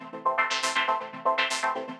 SaS_MovingPad04_120-C.wav